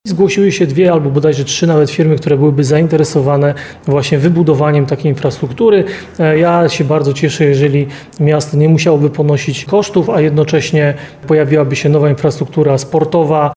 Ja się bardzo cieszę, jeżeli miasto nie musiałoby ponosić kosztów, a jednocześnie pojawiłaby się nowa infrastruktura sportowa – mówi prezydent Nowego Sącza Ludomir Handzel .